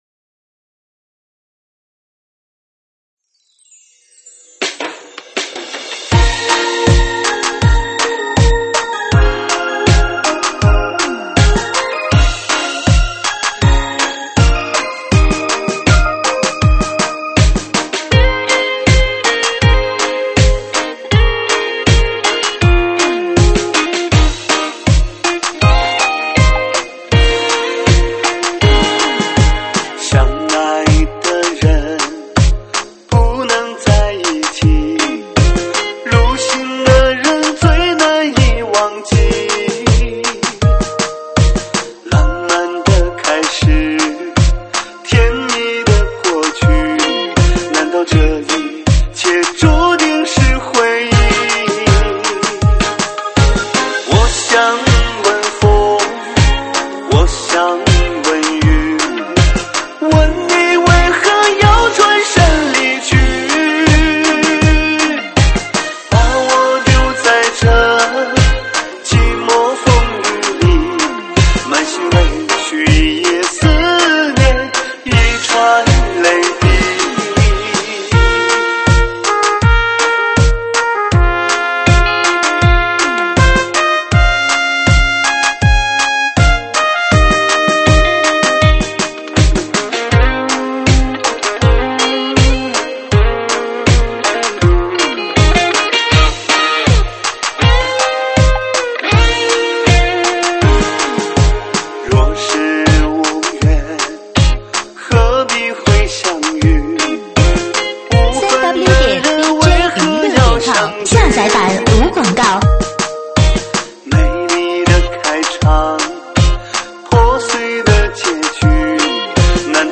收录于(水兵舞)